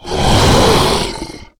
DayZ-Epoch/SQF/dayz_sfx/bloodsucker/attack_3.ogg
attack_3.ogg